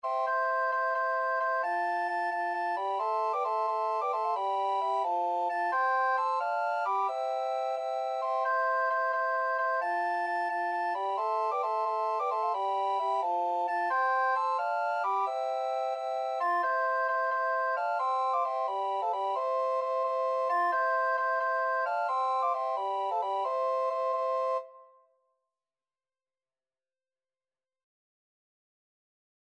Soprano RecorderAlto RecorderTenor RecorderBass Recorder
6/4 (View more 6/4 Music)
Classical (View more Classical Recorder Quartet Music)